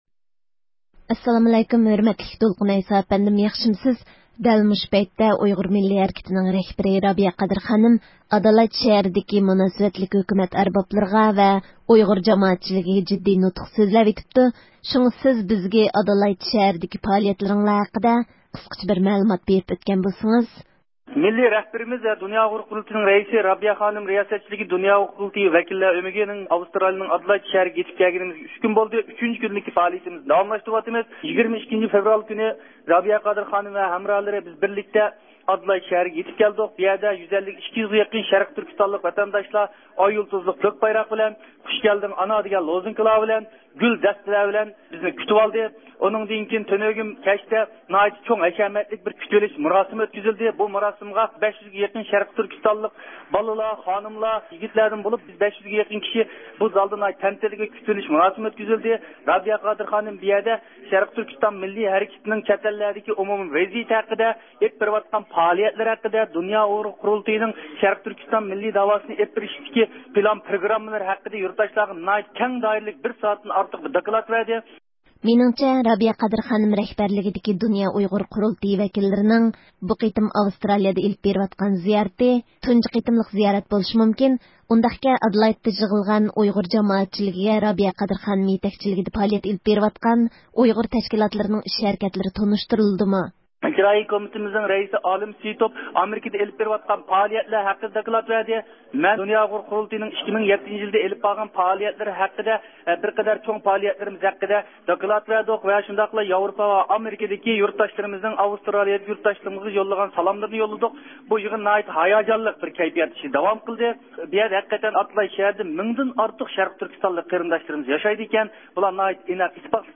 بىز سىلەرگە رابىيە قادىر خانىمنىڭ ئادالايد شەھىرىدىكى پائالىيەتلىرى ھەققىدە تېخىمۇ تەپسىلىي مەلۇمات بېرىش ئۈچۈن، رابىيە قادىر خانىم بىلەن بىرگە زىيارەتتە بولۇۋاتقان دۇنيا ئۇيغۇر قۇرۇلتىيى ۋەكىللىرىدىن بىرى يەنى دۇنيا ئۇيغۇر قۇرۇلتىيىنىڭ باش كاتىپى دولقۇن ئەيسا ئەپەندى بىلەن ئۆتكۈزگەن سۆھبىتىمىزنى دىققىتىڭلارغا سۇنىمىز.